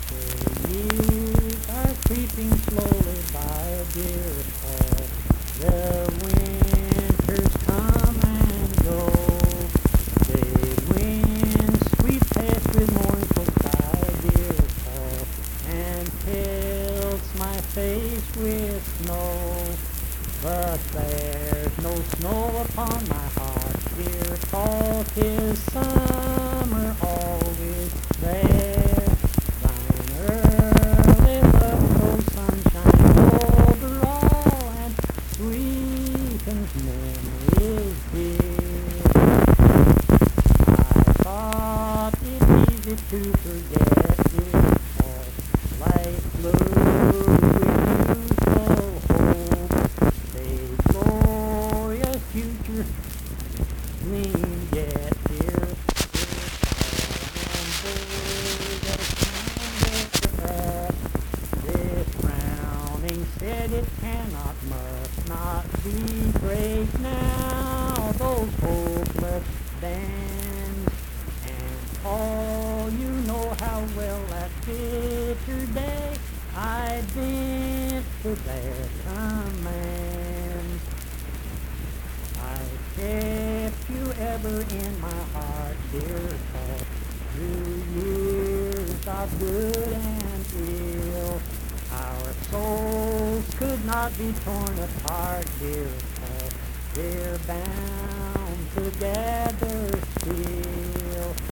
Accompanied (guitar) and unaccompanied vocal music
Performed in Mount Harmony, Marion County, WV.
Voice (sung)